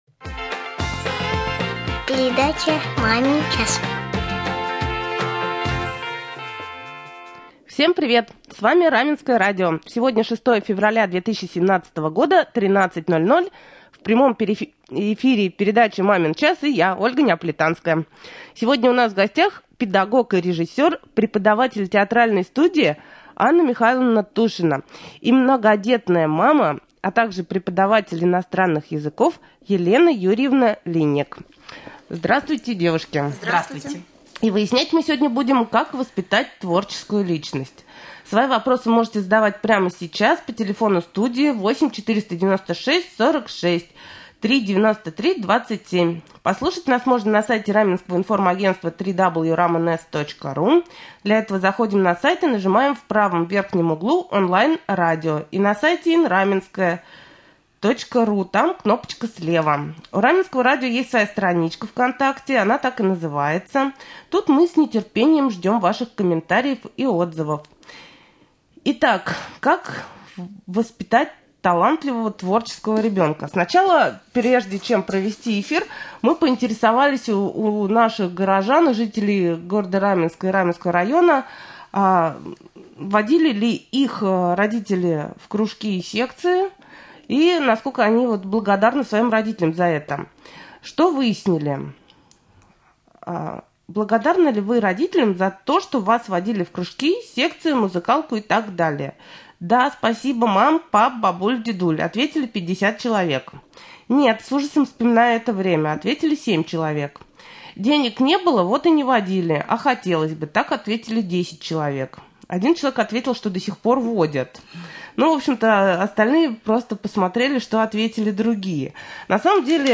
О творческом развитии детей говорили сегодня в Мамин час на Раменском радио